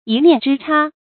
一念之差 注音： ㄧ ㄋㄧㄢˋ ㄓㄧ ㄔㄚˋ 讀音讀法： 意思解釋： 差：差錯。